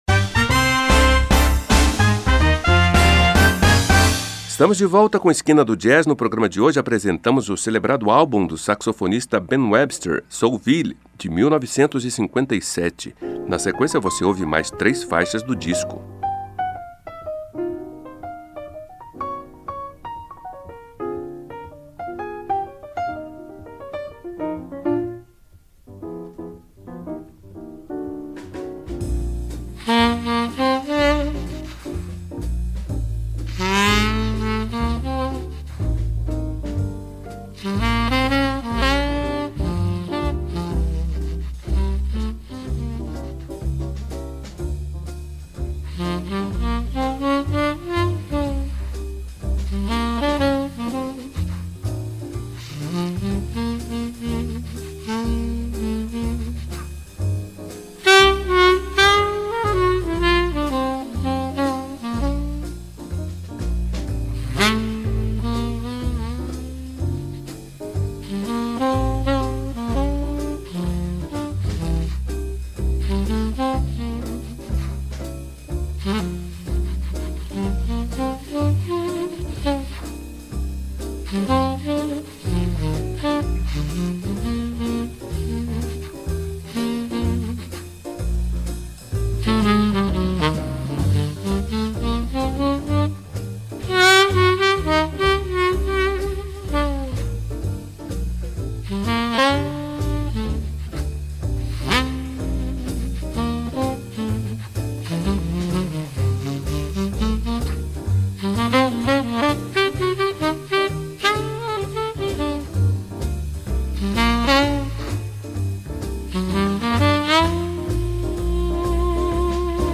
saxofonista tenor
que inclui o piano de outro gigante do jazz